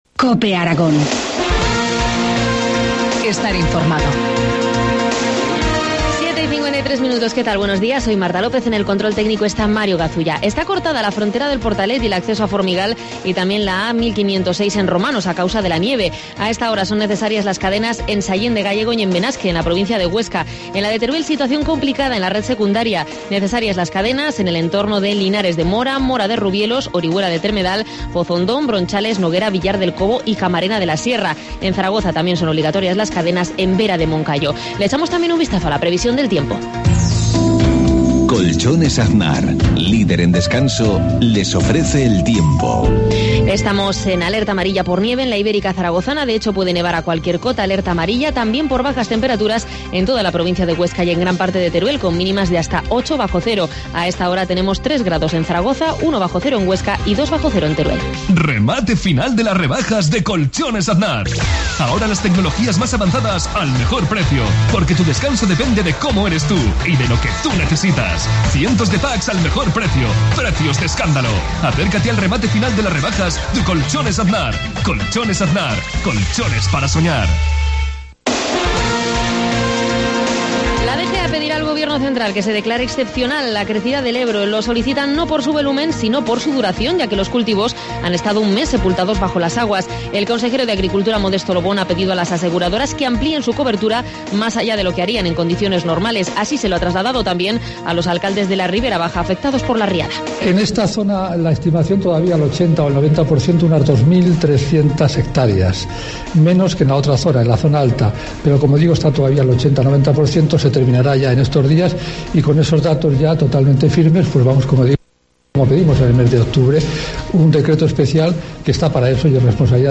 Informativo matinal, martes 26 de febrero, 7.53 horas